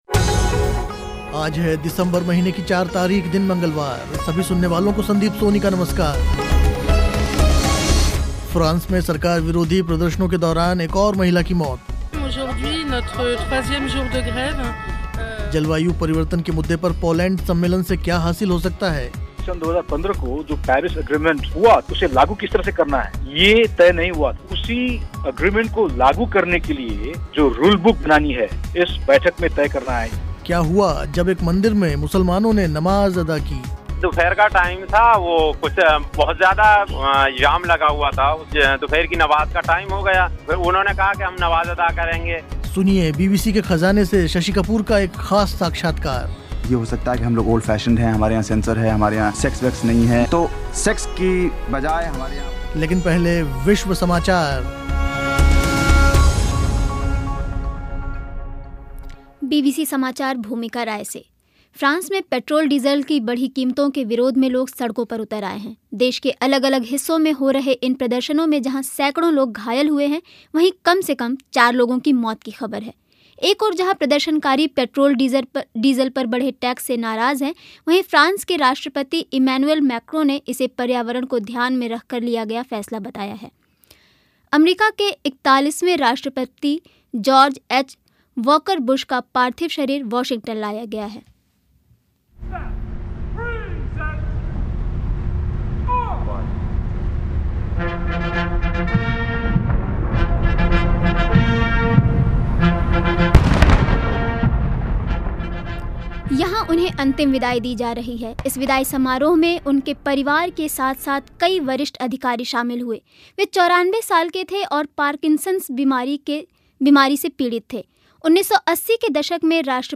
बीबीसी के ख़ज़ाने से अभिनेता शशि कपूर से एक ख़ास साक्षात्कार.